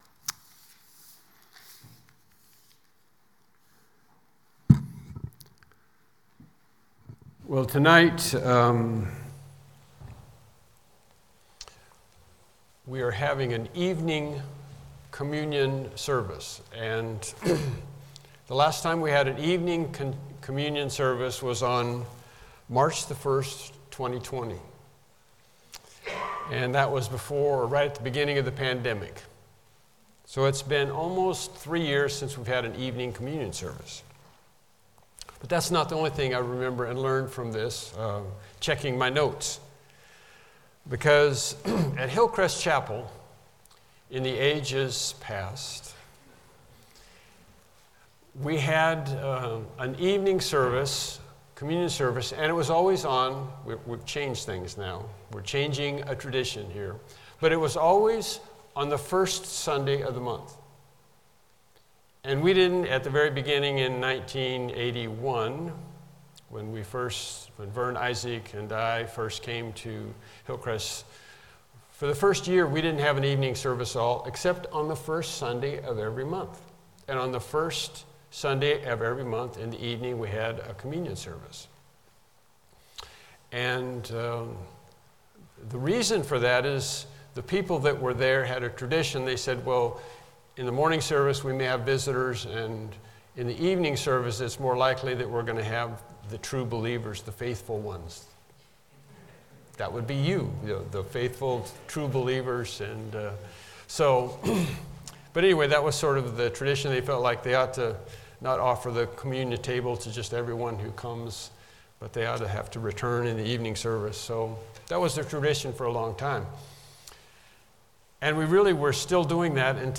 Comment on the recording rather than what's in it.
Evening Sermons , The Study of Things to Come Service Type: Evening Worship Service « The Parable from the Fig Tree